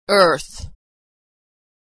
Consonant Sound Voiced r = /ər/